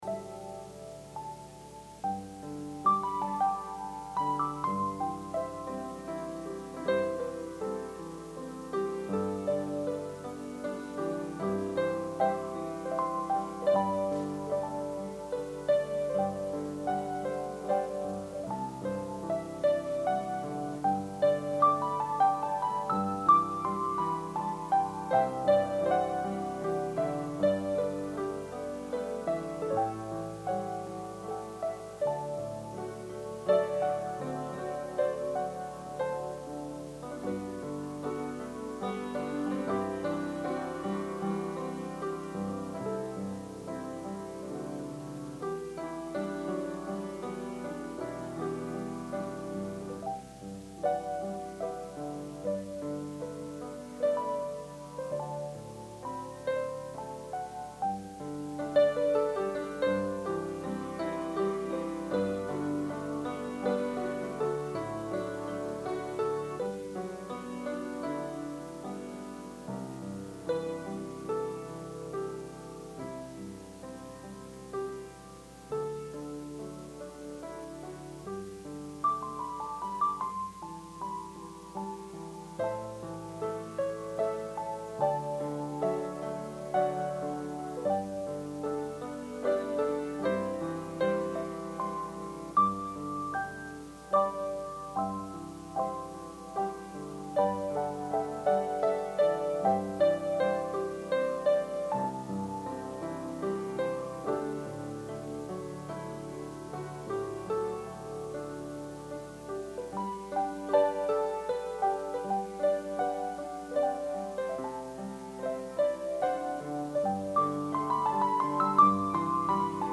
Sermon – August 30, 2020